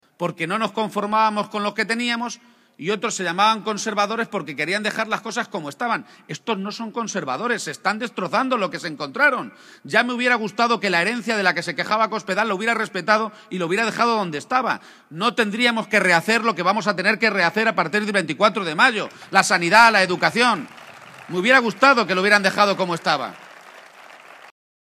Audio Page en Villarrobledo 3